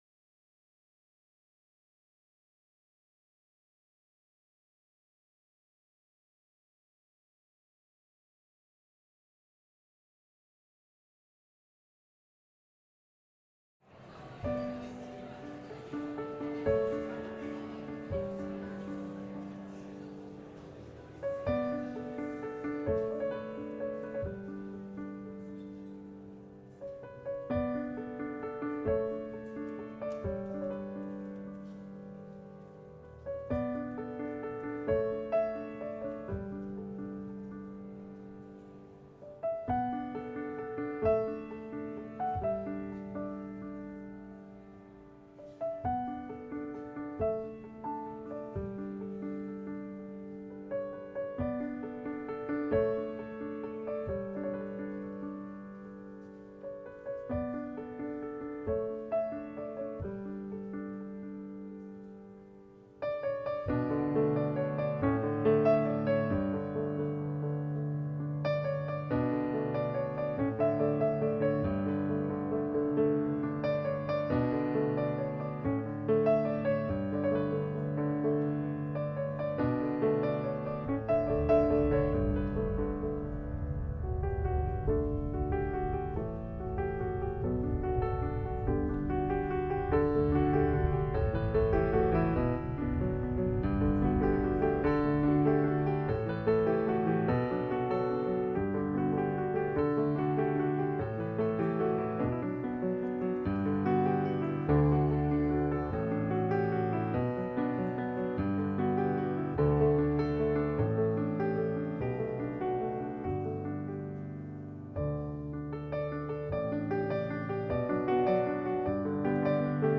based on a 12th-century folk song from Mali